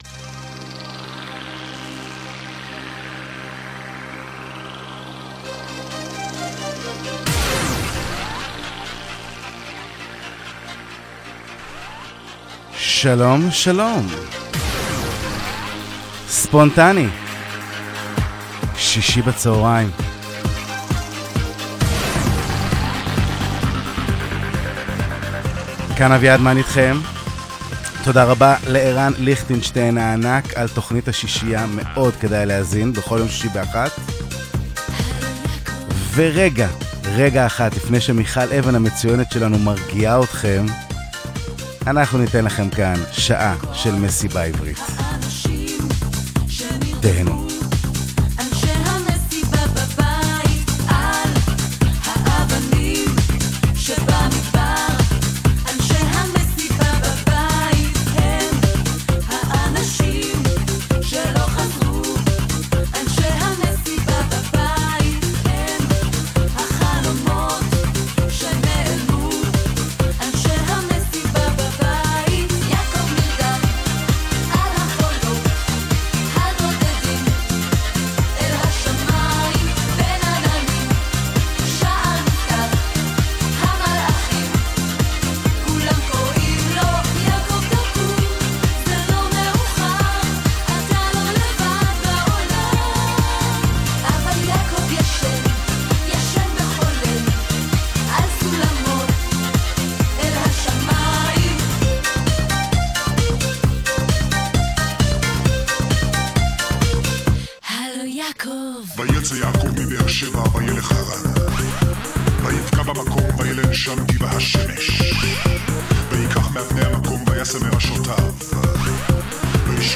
מסיבה עברית לכבוד החגים – אז יאללה, לרקוד!
70s 80s 90s ישראלי